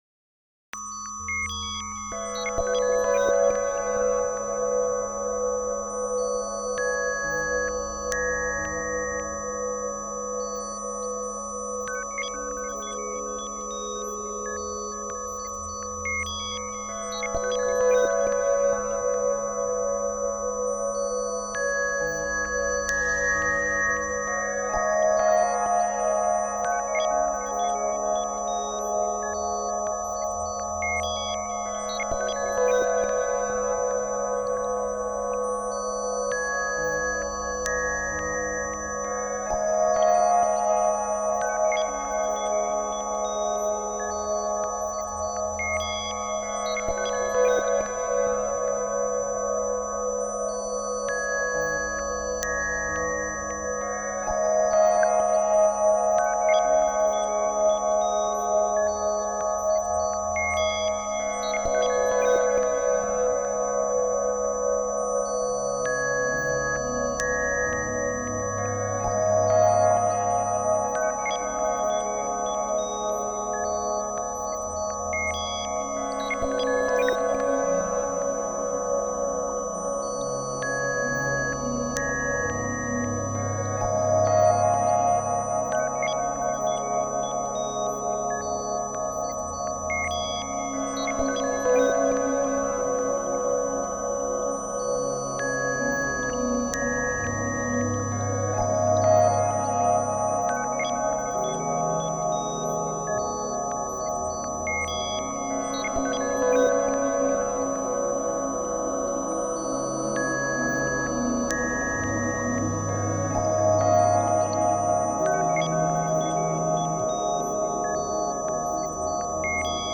introspective and spatial ambient, of the dark kind